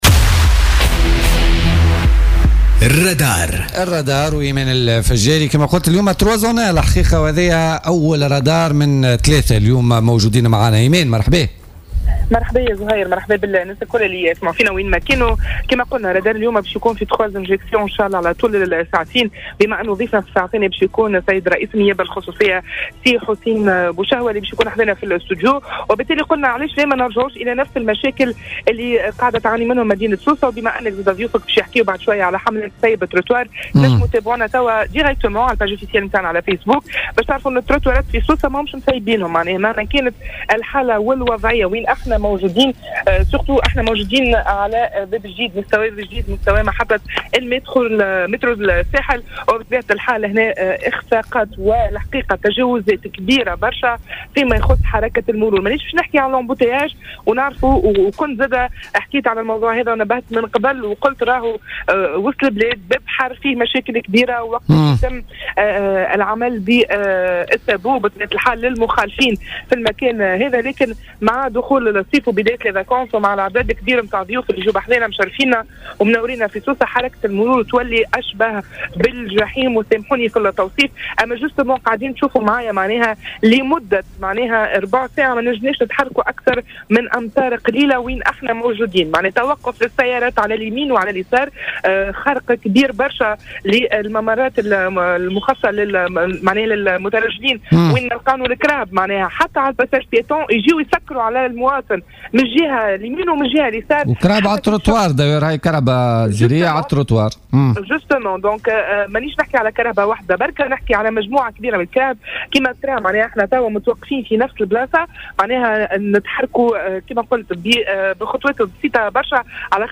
تجوّل فريق "الرادار" اليوم الخميس وسط سوسة انطلاقا من منطقة باب الجديد لنقل حالة الاختناق المروري.